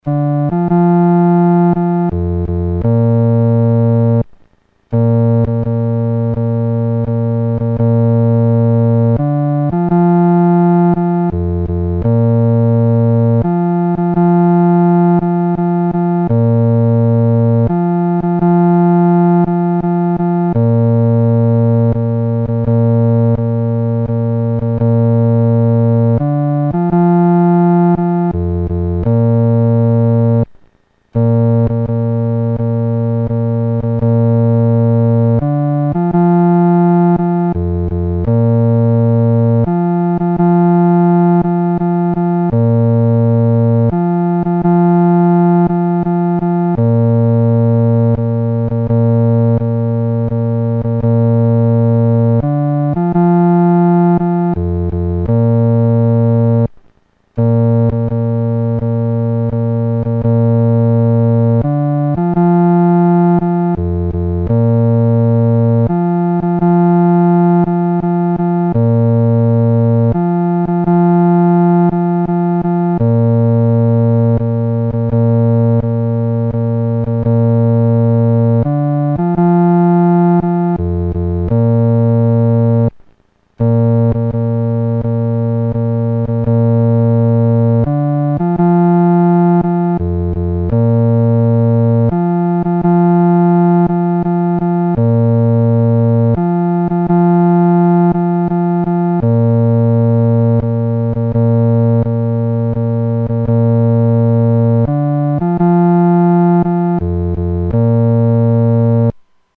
独奏（第四声）
万古磐石-独奏（第四声）.mp3